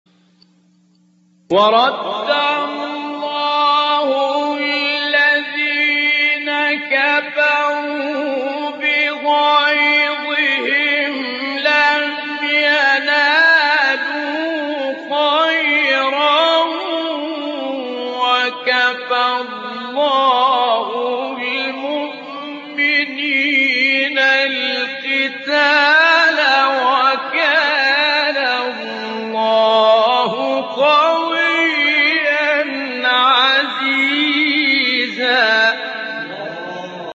گروه شبکه اجتماعی: مقاطع صوتی از سوره احزاب با صوت سید متولی عبدالعال ارائه می‌شود.
به گزارش خبرگزاری بین المللی قرآن (ایکنا) فرازهایی صوتی از سوره مبارک احزاب با صدای سید متولی عبدالعال، قاری به نام مصری در کانال تلگرامی تلاوت ناب منتشر شده است، در زیر ارائه می‌شود.